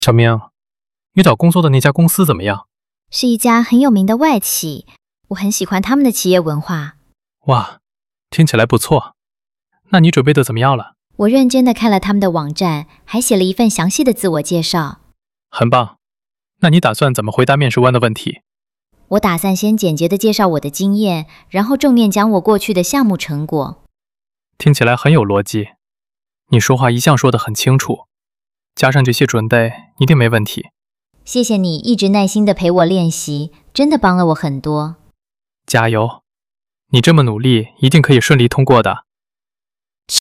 hoi-thoai-moi.mp3